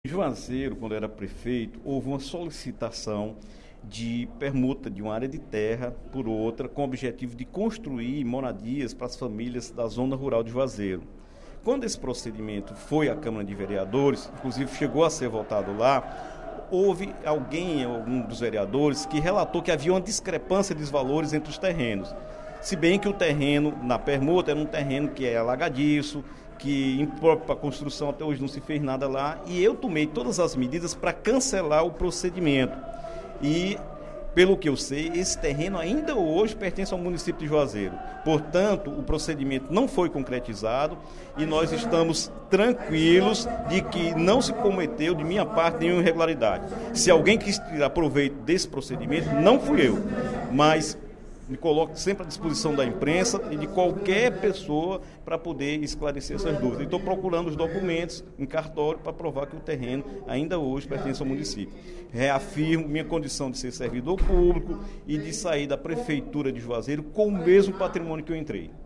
O deputado Dr. Santana (PT) esclareceu, nesta terça-feira (07/06), no primeiro expediente da sessão plenária, matéria publicada no site Miséria acerca de permuta de um terreno em Juazeiro do Norte para a construção de moradias na zona rural, o que teria ocorrido durante sua gestão como prefeito do município.